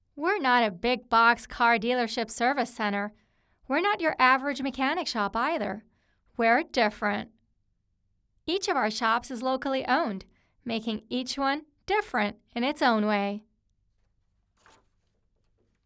Bumps along my wavelength when recording noise floor
Here you go! raw file, with no editing at all.